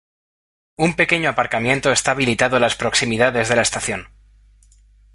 /apaɾkaˈmjento/